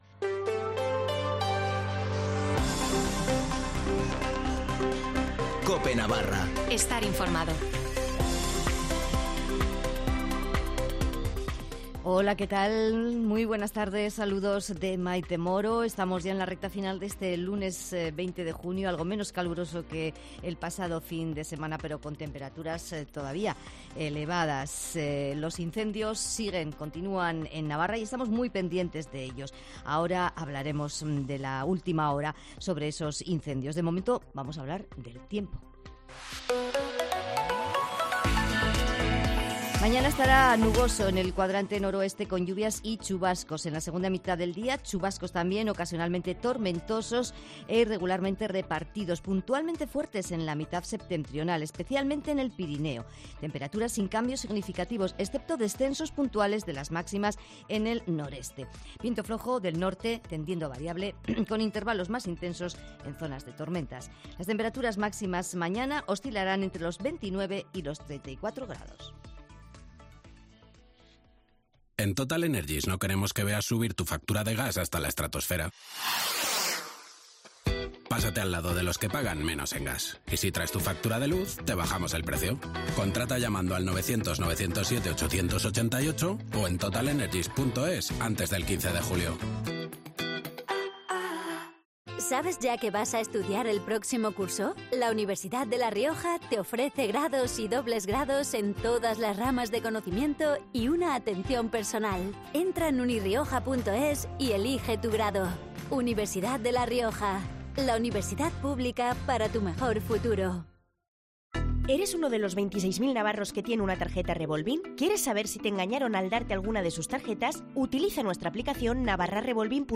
Informativo de las 19:50 en Cope Navarra (20/06/2022)